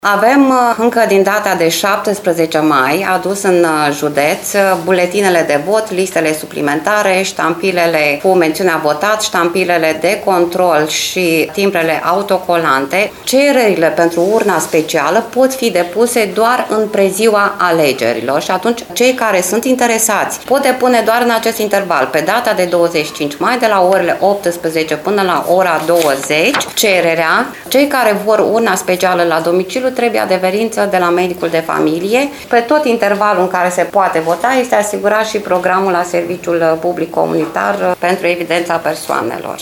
Prefectul MIRELA ADOMNICĂI a declarat că bugetul total alocat județului Suceava este de 4 milioane și jumătate lei.